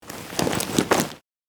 Download Free Clothing Sound Effects | Gfx Sounds
Cloth-bag-handle.mp3